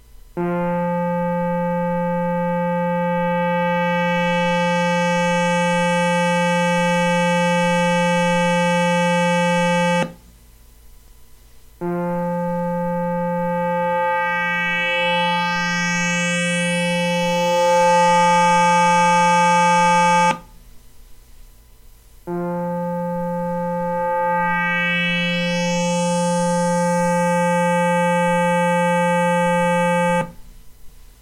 Sample 11: A steady tone played as the wave folder depth control is advanced from 0 to 10 with the modulation off. The tone is played three times, with shape settings of 0, 2, and 5 respectively.